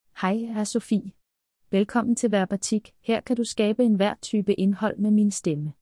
Sofie — Female Danish AI voice
Sofie is a female AI voice for Danish.
Voice sample
Listen to Sofie's female Danish voice.
Sofie delivers clear pronunciation with authentic Danish intonation, making your content sound professionally produced.